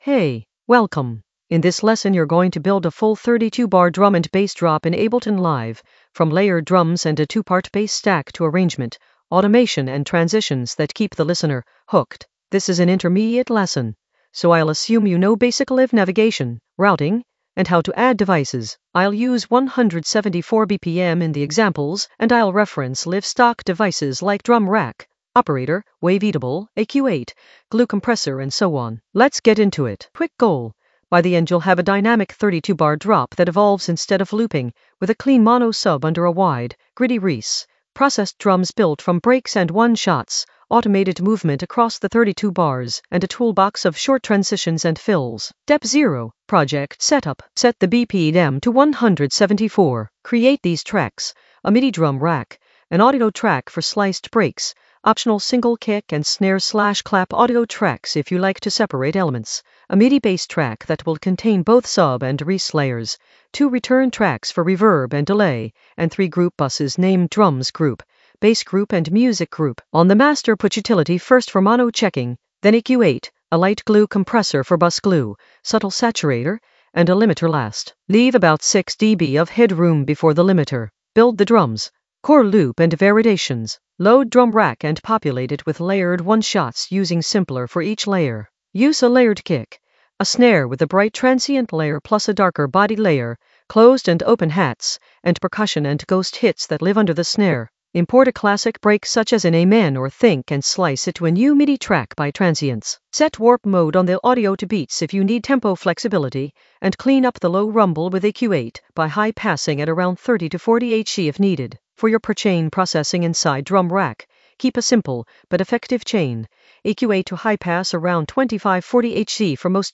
An AI-generated intermediate Ableton lesson focused on Building a full 32 bar drop in the Arrangement area of drum and bass production.
Narrated lesson audio
The voice track includes the tutorial plus extra teacher commentary.